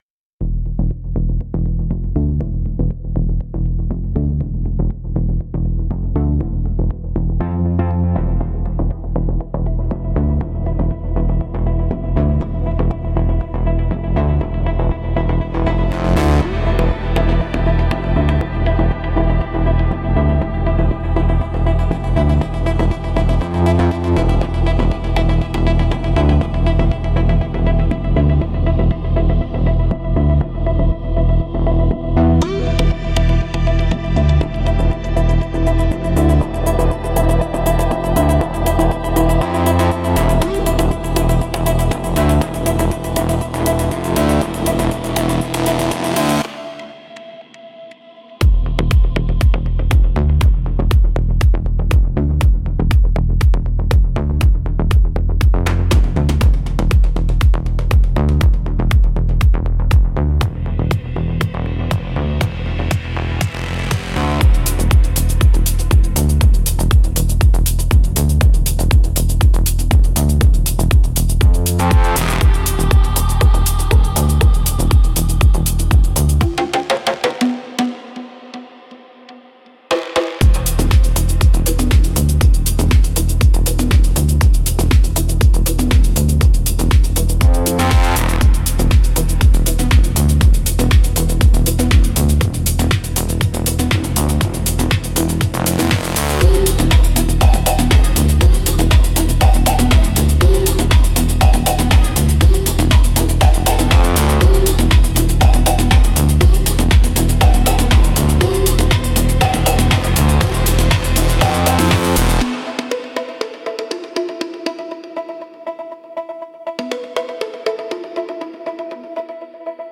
Instrumentals - Chant of the Digital Dawn